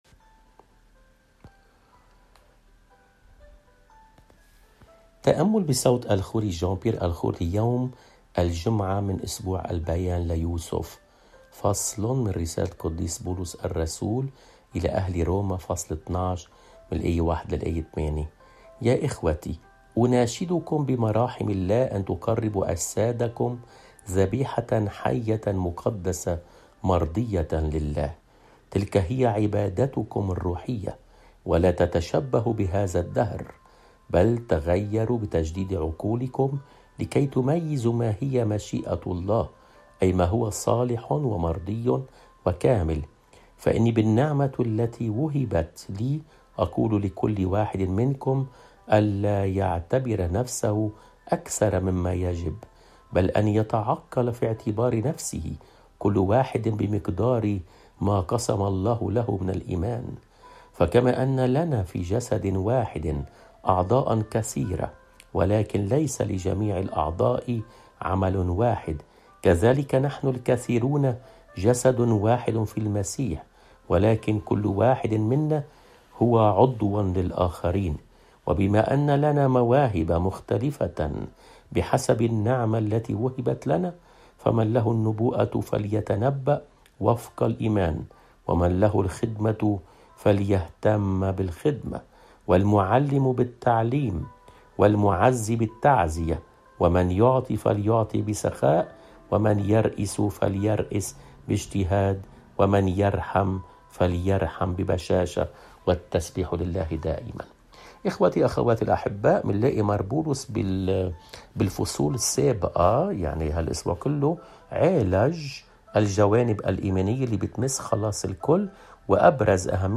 الرسالة